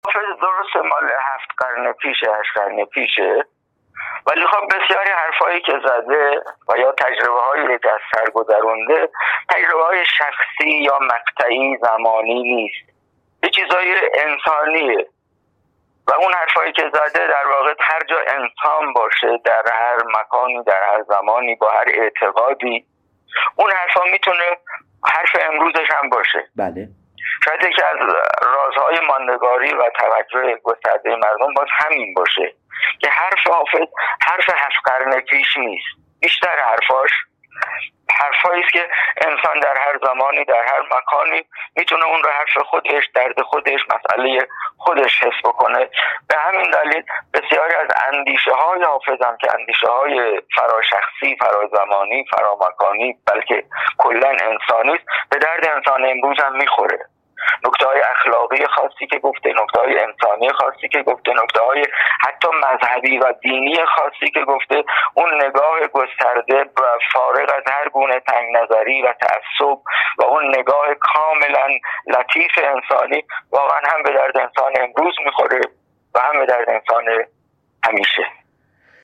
بربلندای امواج تلفن